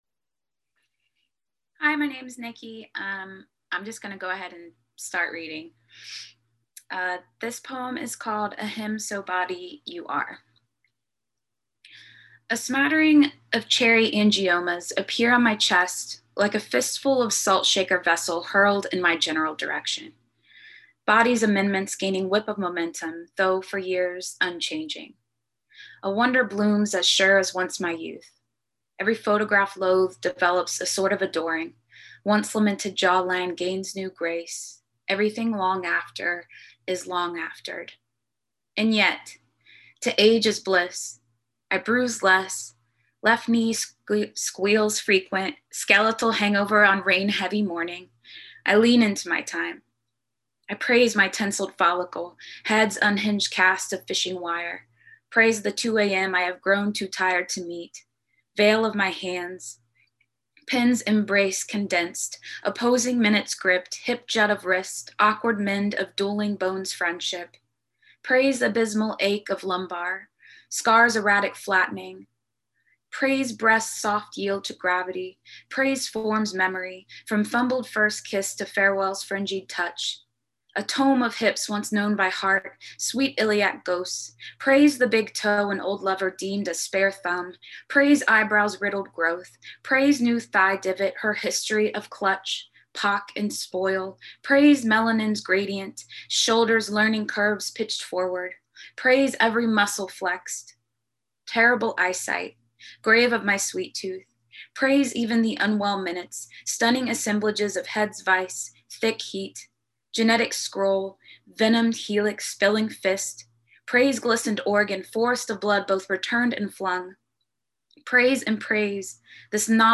Note that a link for the entire reading is available at the bottom of this post.